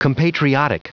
Prononciation du mot compatriotic en anglais (fichier audio)
Prononciation du mot : compatriotic